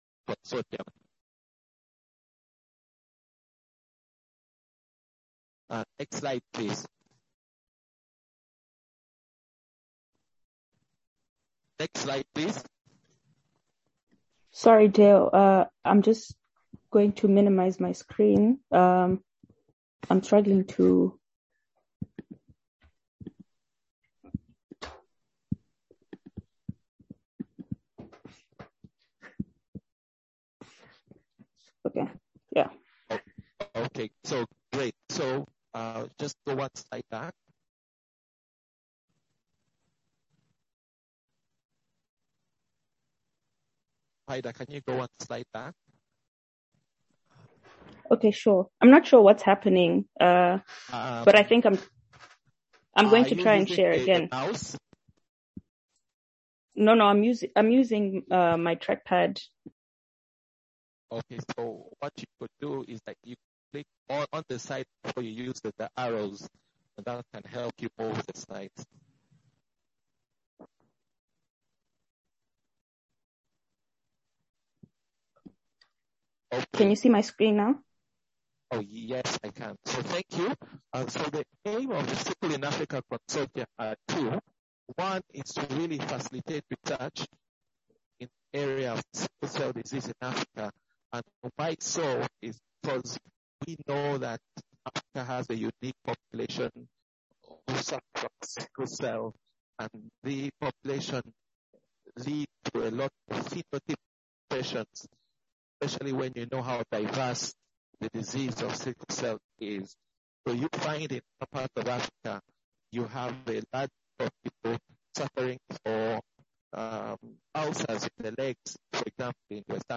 This consortium webinar is organised in commemoration of world sickle cell day. The goal is to bring together different stakeholder groups and sister networks to discuss and share experiences and opportunities for collaboration and for expanding sickle cell disease research in Africa and new collaborations.